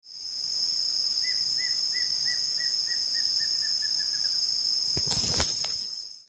Choca Amarilla (Dysithamnus mentalis)
Nombre en inglés: Plain Antvireo
Localidad o área protegida: San Ignacio
Condición: Silvestre
Certeza: Vocalización Grabada
Choca-Amarilla.mp3